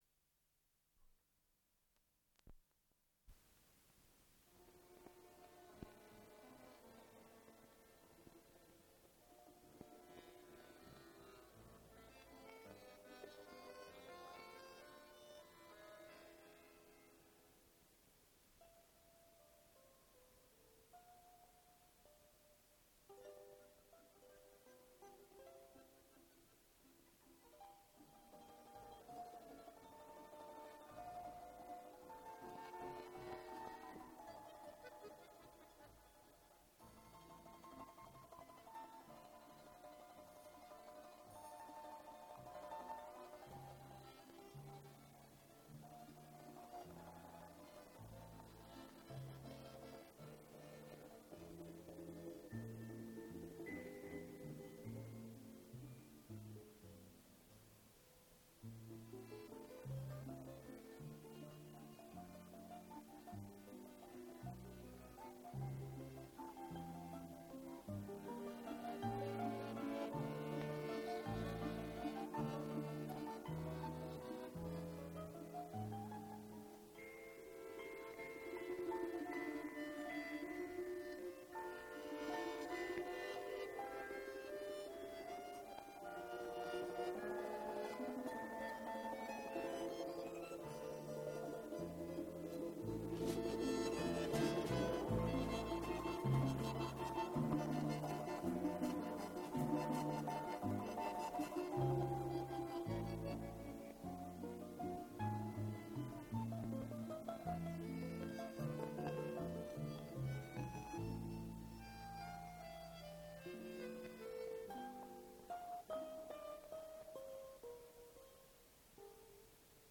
русский  инструментальный ансамбль
балалайка
баян
ударные.
Дубль моно.